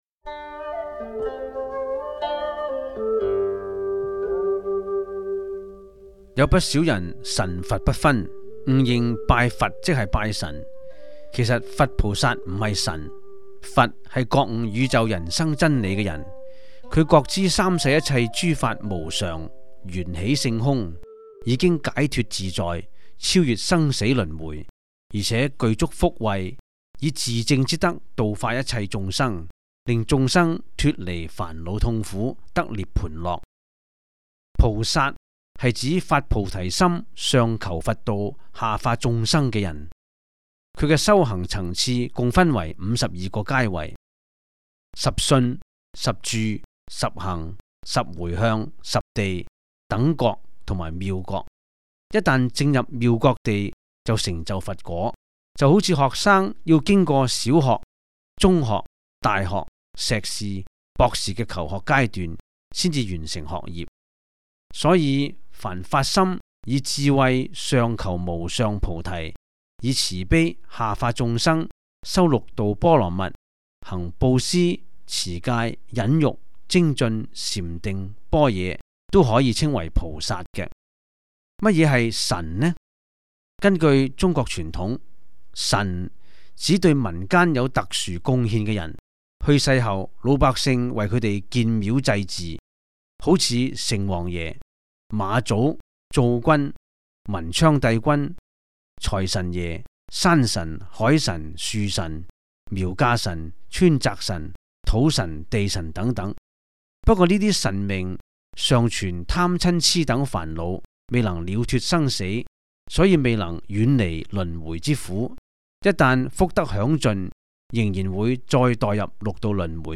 第 三 十 一 辑    (粤语主讲 MP3 格式)